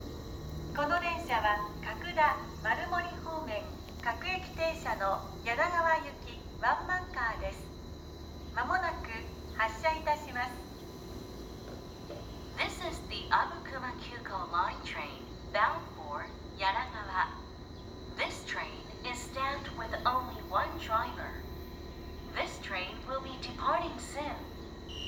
【発車案内】各停・梁川(ﾜﾝﾏﾝ)
発車案内は車外スピーカーから流れます。